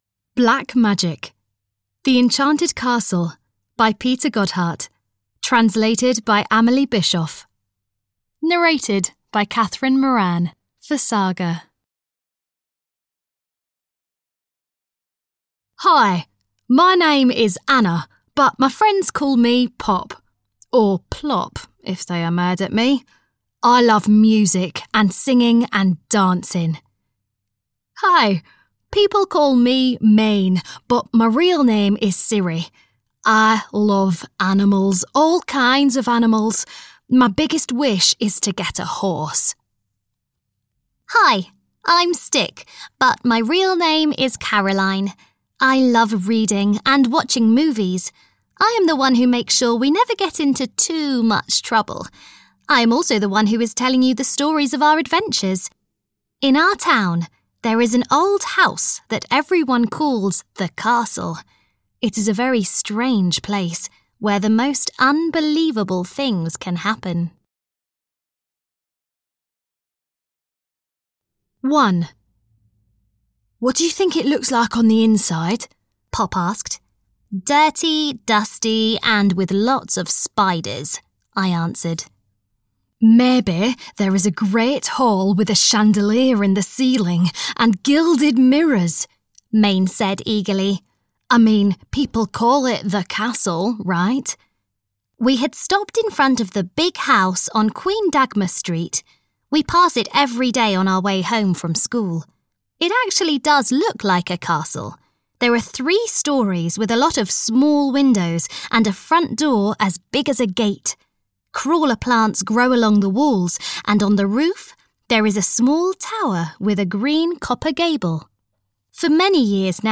Audio knihaThe Enchanted Castle 1 - Black Magic (EN)
Ukázka z knihy